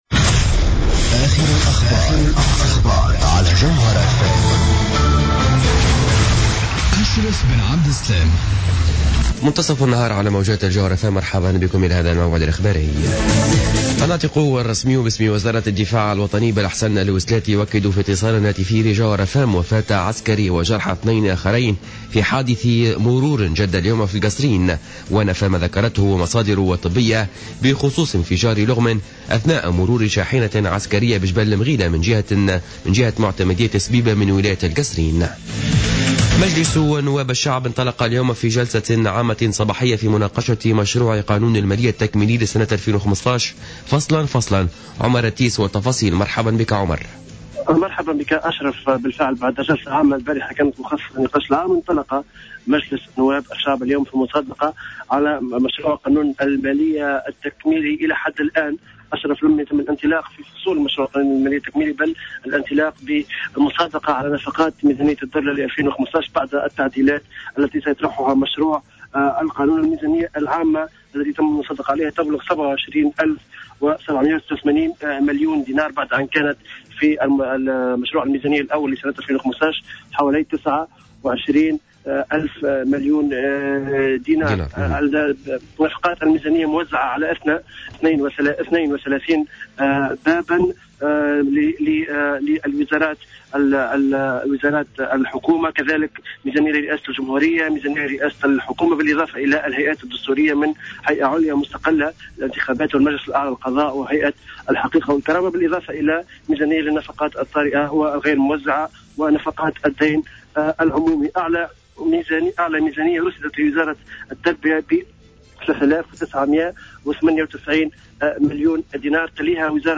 نشرة أخبار منتصف النهار ليوم الاربعاء 5 أوت 2015